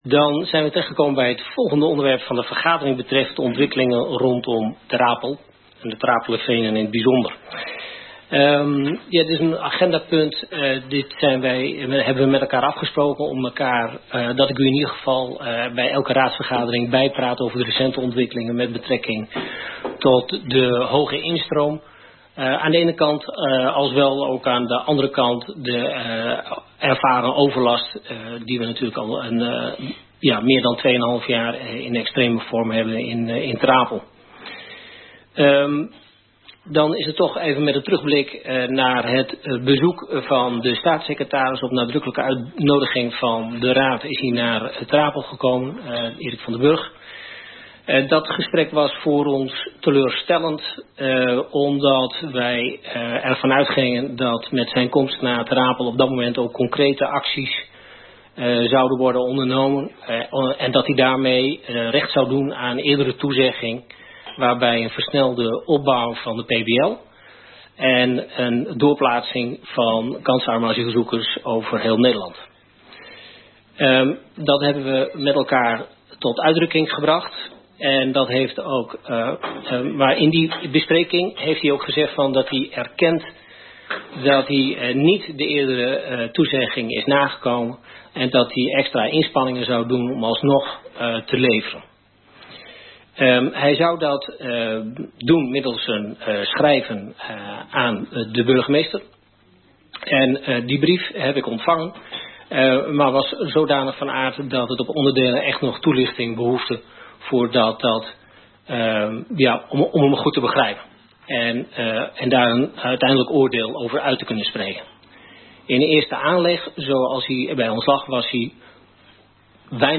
Geluidsverslag raadsvergadering 1 november 2023